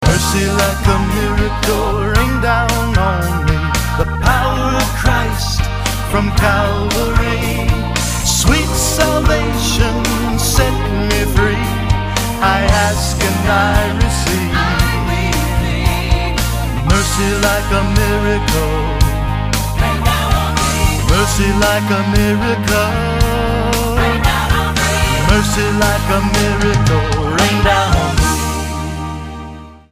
STYLE: Country
Uncomplicated country-tinged pop